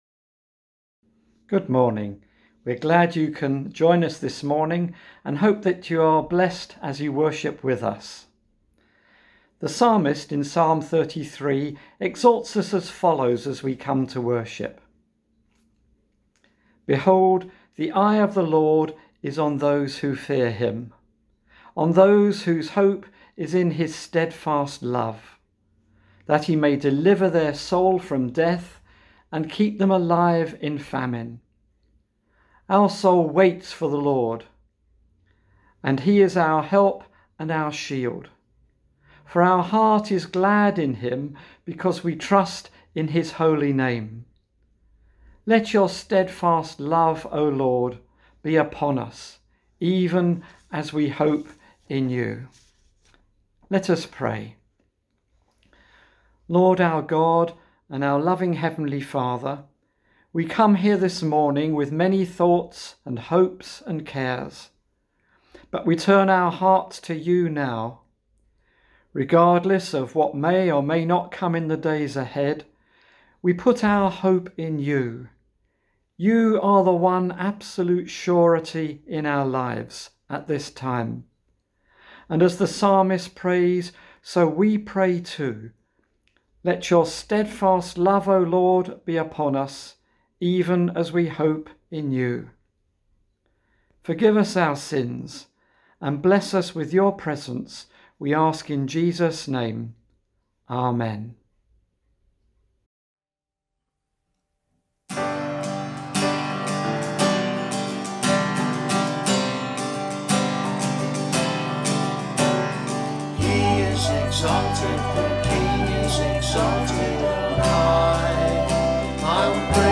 Morning Service , Guest Speaker